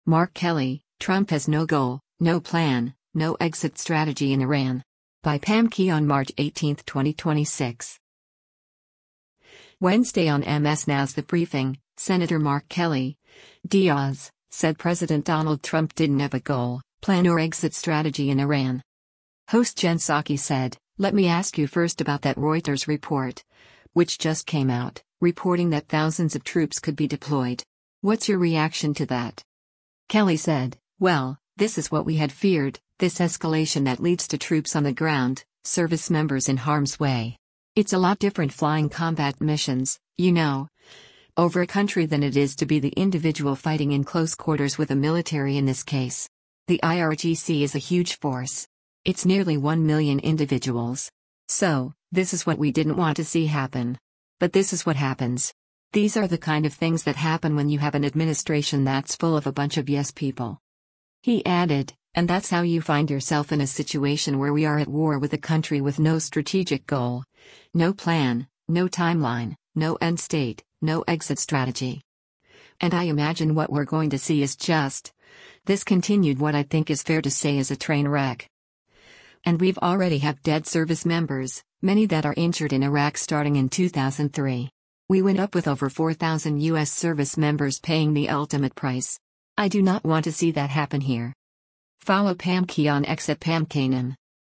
Wednesday on MS NOW’s “The Briefing,” Sen. Mark Kelly (D-AZ) said President Donald Trump didn’t have a goal, plan or exit strategy in Iran.